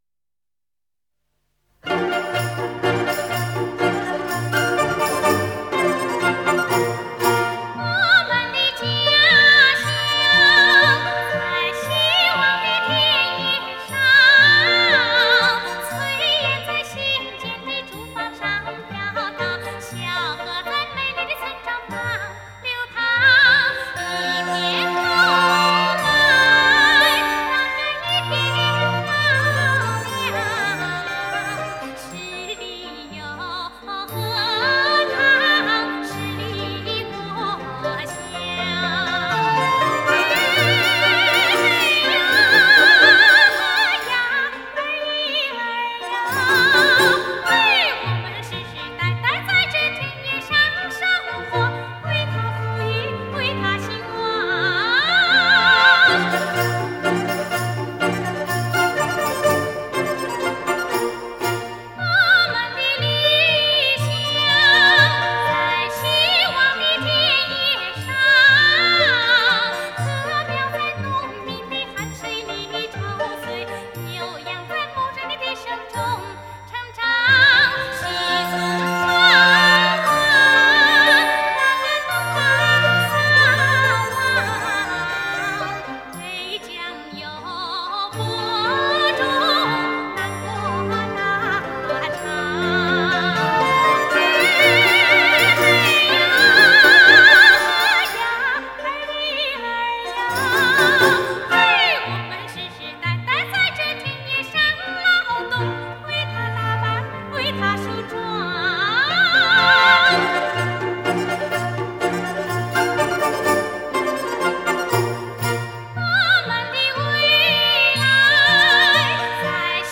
这些歌曲基本上都是我自己从CD转录下来的，尽量保证原唱。